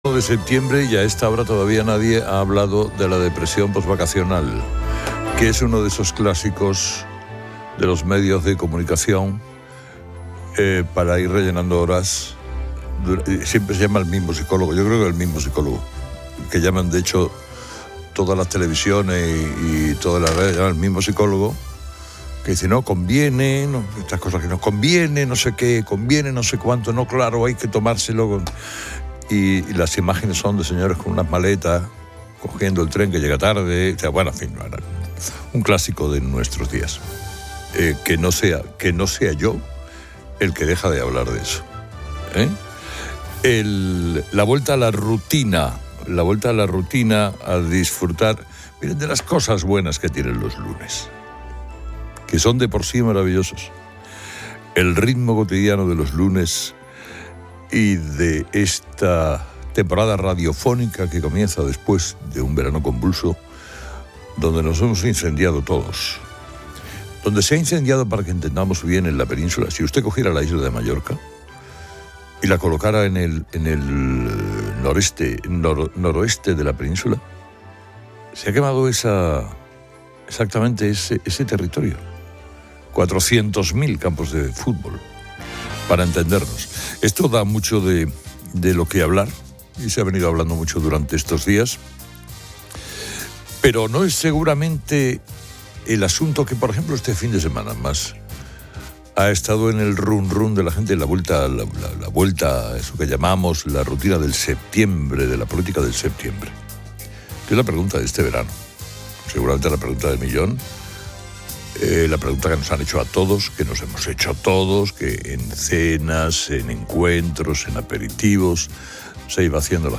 El audio inicia con un comentario irónico sobre la "depresión postvacacional" y el inminente regreso a la rutina. Pasa luego a discutir la magnitud de los incendios forestales de este verano en la Península Ibérica, usando la metáfora de Mallorca ardiendo. La conversación deriva hacia la situación política española, planteando la pregunta recurrente sobre la continuidad de Pedro Sánchez en el poder.
Finalmente, un segmento de noticias económicas informa que los españoles gastaron 3.000 millones de euros menos este verano en comparación con años anteriores, a pesar de que las vacaciones resultaron ser las más caras.